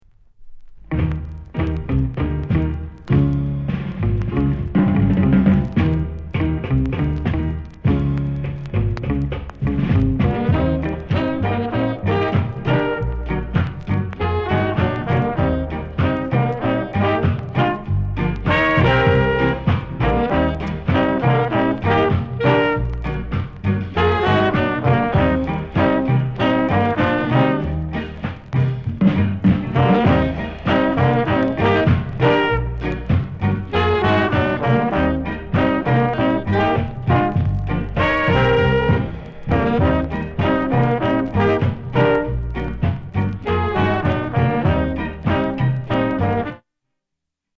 ROCKSTEADY INST